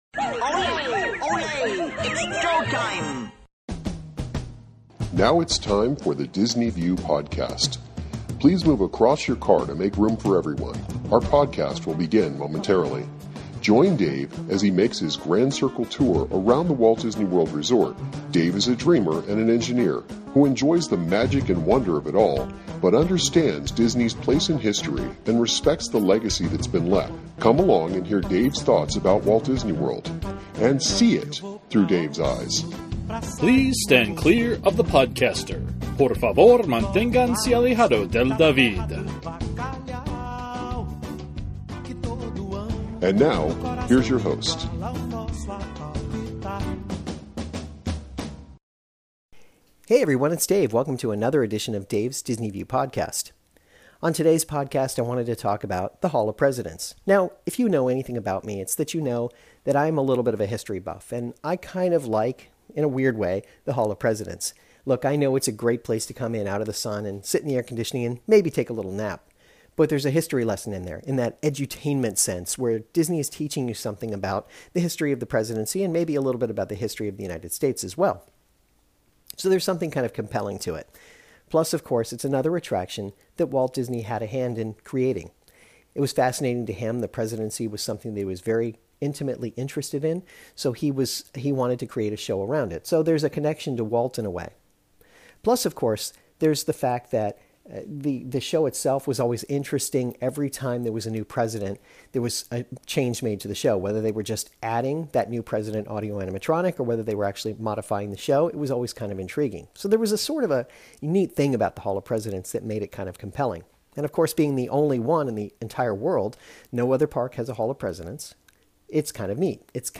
Today, I present to you the audio from the current show, minus the speaking part at the end.